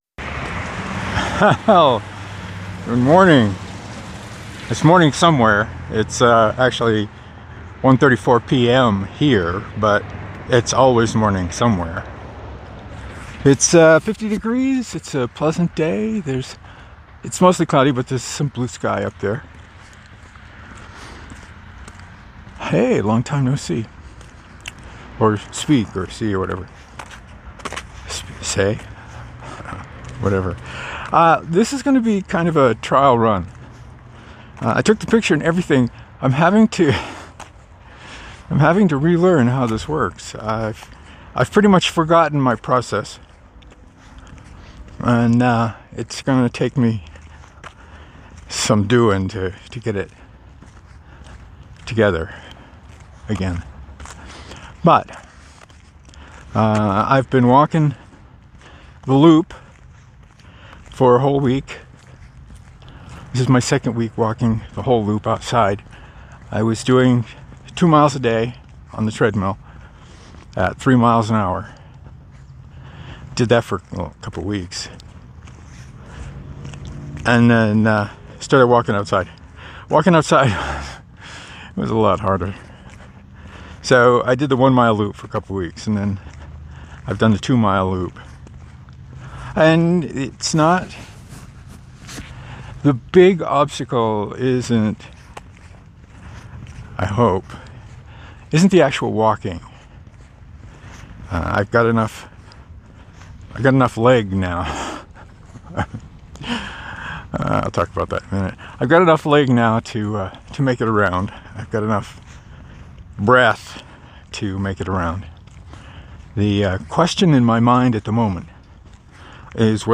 Didn’t recognize your voice initially – – seems higher in pitch and, if possible, younger than it did before the surgery.